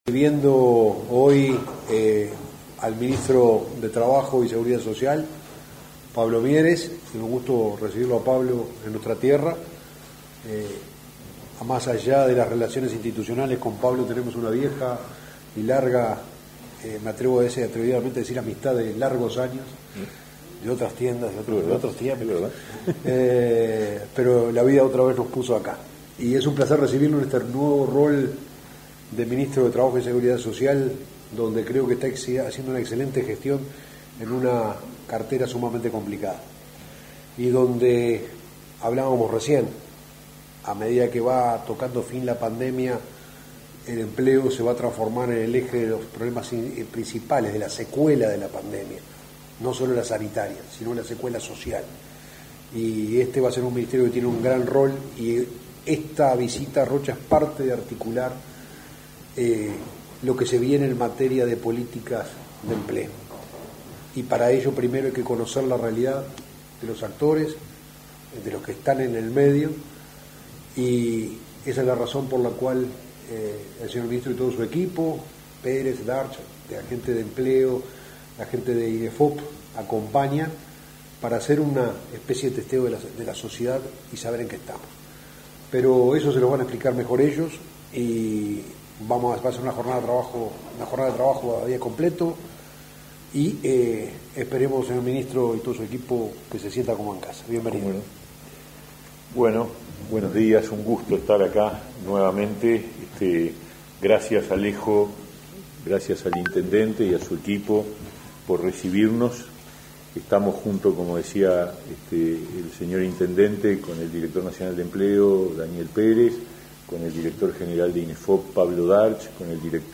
Conferencia de autoridades del Ministerio de Trabajo y Seguridad Social e Intendencia de Rocha